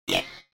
جلوه های صوتی
دانلود صدای ربات 10 از ساعد نیوز با لینک مستقیم و کیفیت بالا